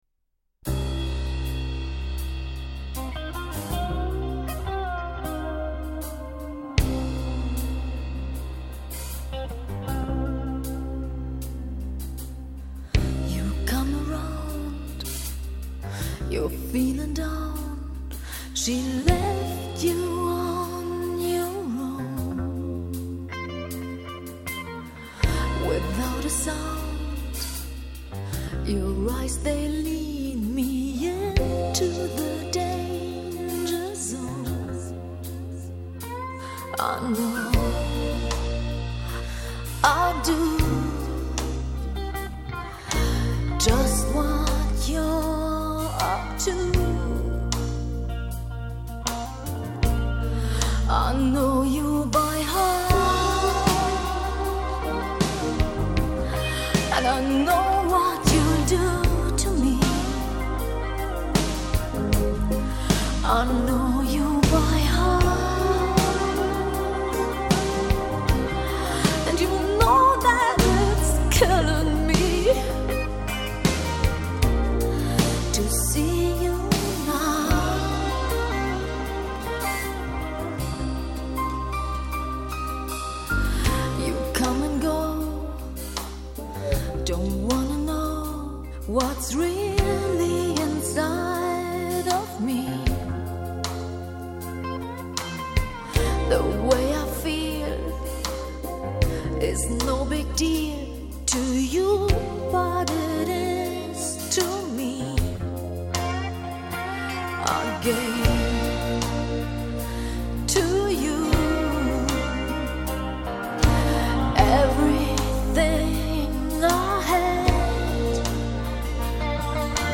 Жанр: classicmetal